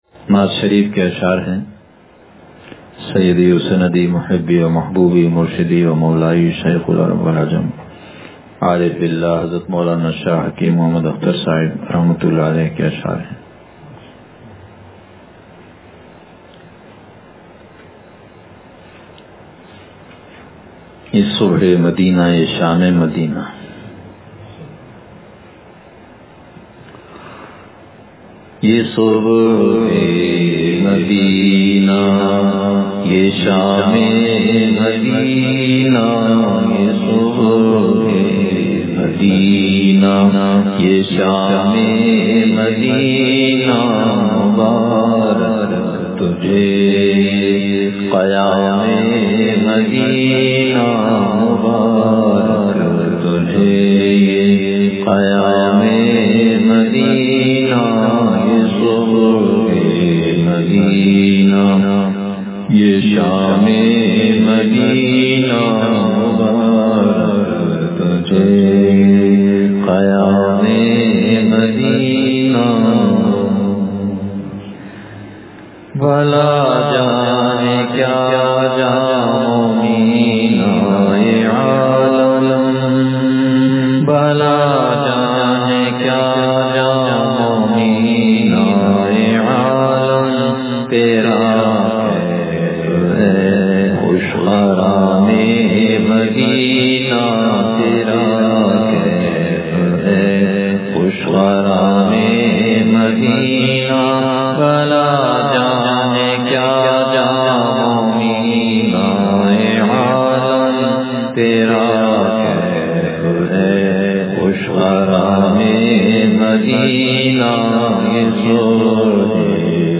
یہ صبح مدینہ یہ شامِ مدینہ – مجلس بروز جمعرات